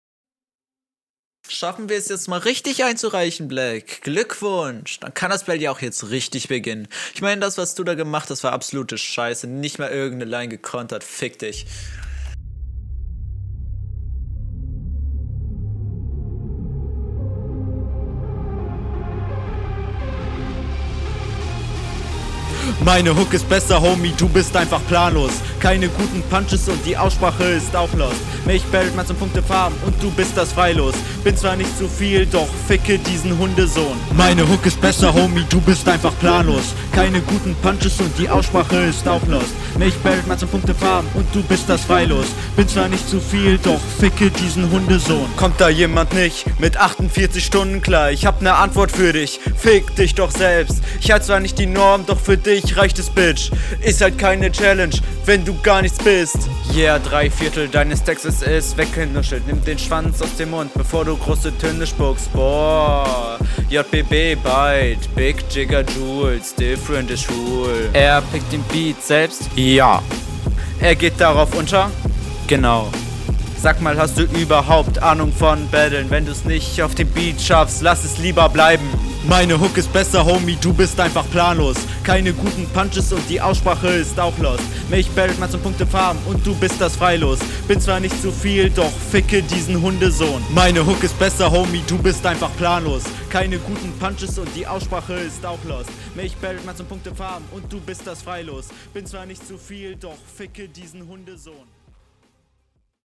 Du bist besser verständlich, aber die hook war leider nicht besser. texlich etwas stärker aber …
sehr schlechte reime. bis auf einige flowfehler relativ im takt, allerdings komischer stimmeinsatz. audioqualität ist …